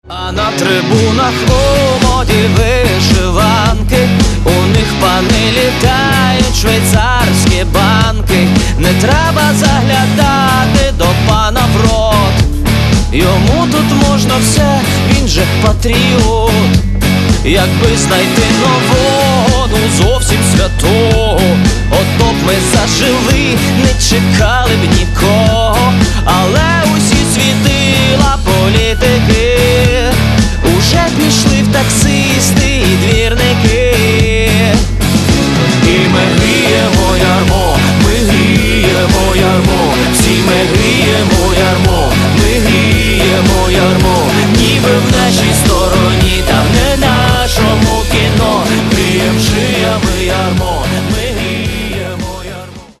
Каталог -> Рок и альтернатива -> Просто рок